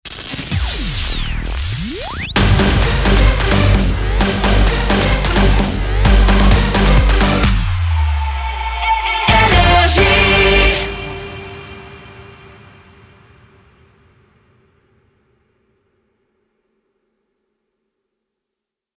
Jingle 2